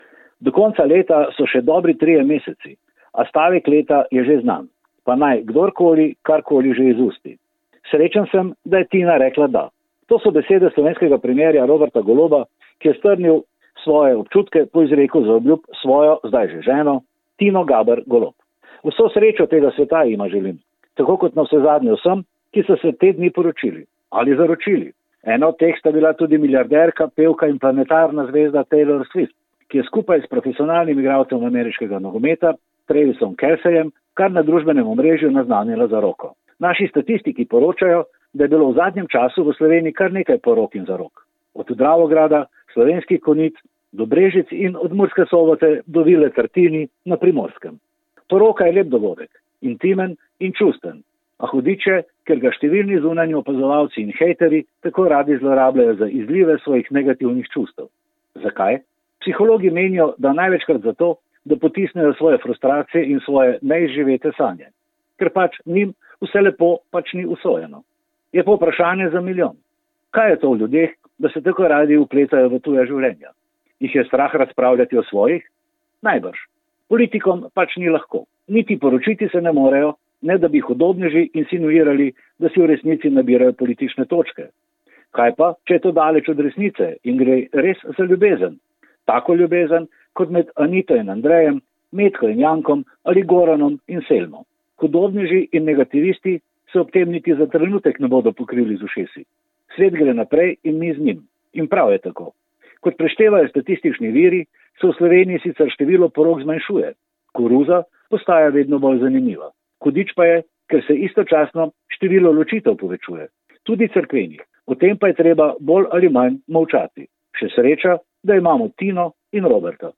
Komentar je stališče avtorja in ne nujno tudi uredništva.